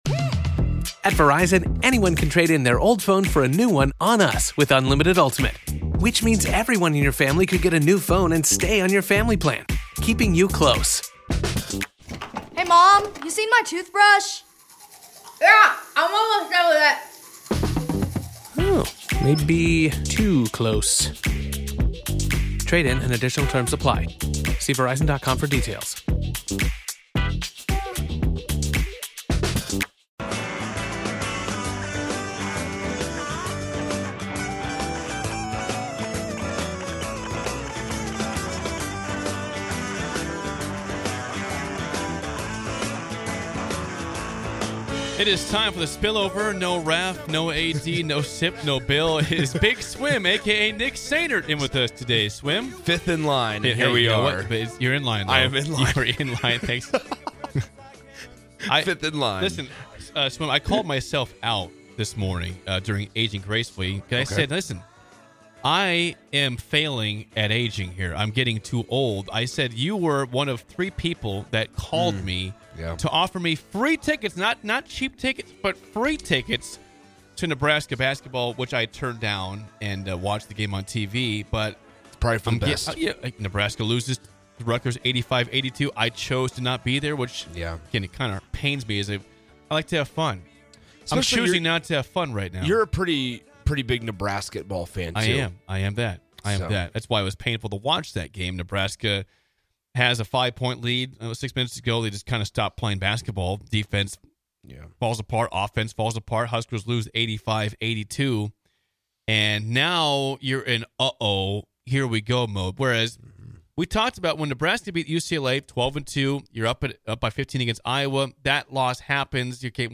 Join these three goofballs from 6-8am every weekday morning for the most upbeat and energetic morning show you'll ever experience. Grab a cup of coffee, turn up the volume, and imagine you're right alongside them in studio!!